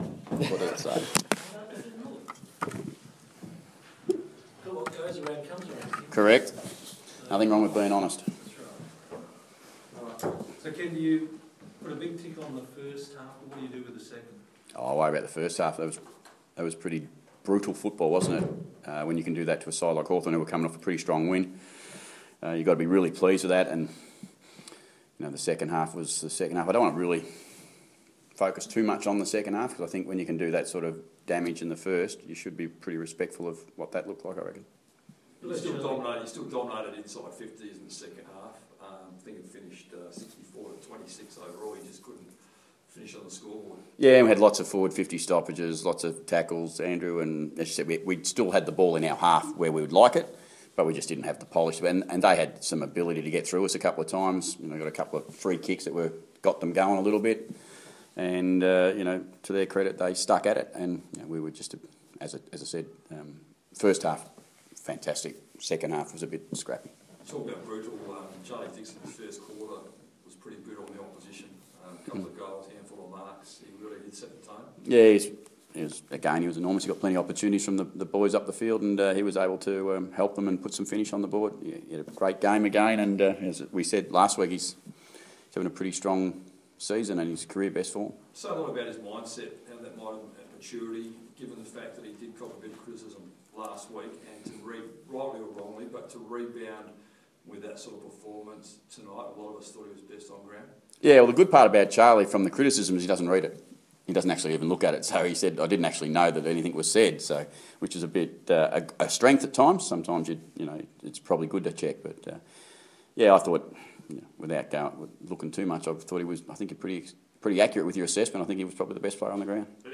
Ken Hinkley post-match press conference - Saturday, 1 June, 2017